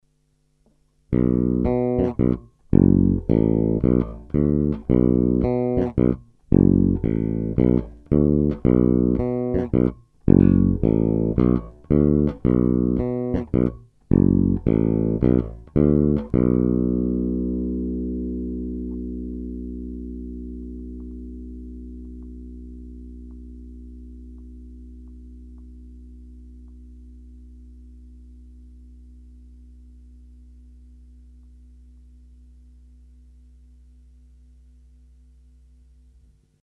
Naprosto typický Jazz Bass, ve zvuku hodně vnímám rezonanci samotného nástroje a desítky let jeho vyhrávání.
Není-li uvedeno jinak, následující nahrávky jsou vyvedeny rovnou do zvukovky, s plně otevřenou tónovou clonou a jen normalizovány, jinak ponechány bez úprav.
Kobylkový snímač